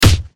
Punch2.wav